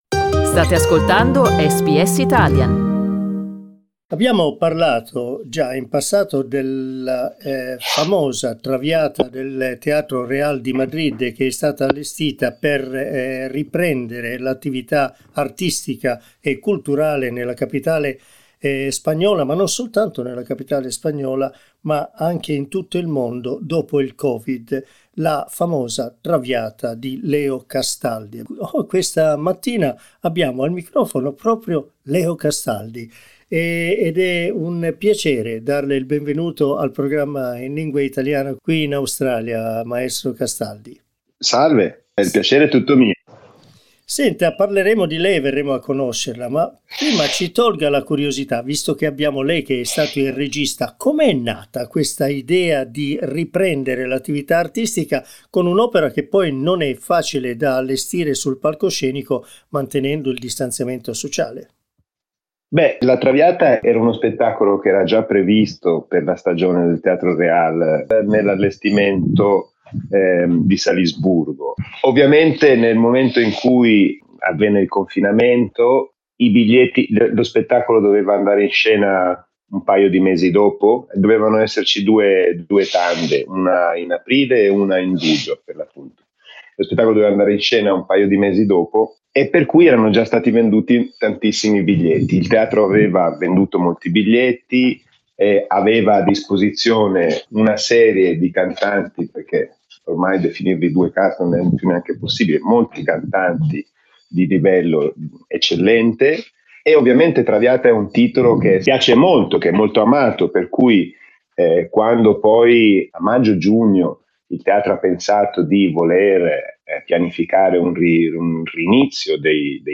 In this interview he explains how he decided to divide the stage into squares clearly marked with red lines.